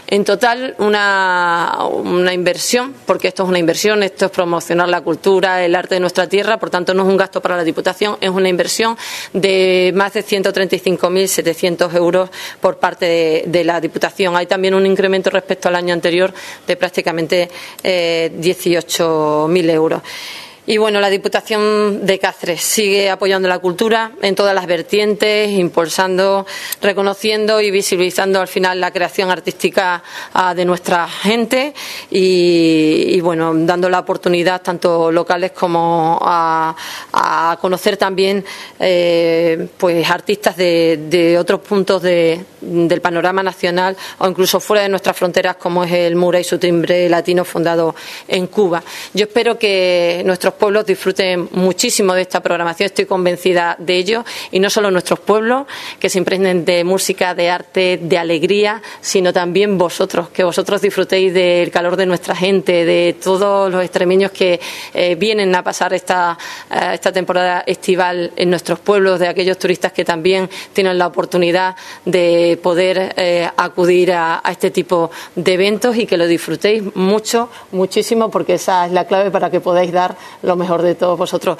Así, con una inversión de 135.700 euros, desde el Área de Cultura de la Diputación de Cáceres se ha elaborado un cuidado programa, en el que se ha buscado conjugar distintos estilos musicales y las demandas de los Ayuntamientos, “es muy difícil programar a la carta en cuanto a las peticiones de los pueblos en otras ediciones no lo habíamos hecho y en esta ocasión si hemos decidido levantar el teléfono y hacerlo un poquito más personalizado”, ha explicado la Vicepresidenta 1ª de Territorio, Igualdad y Cultura, Esther Gutiérrez, en la rueda de prensa de presentación del programa.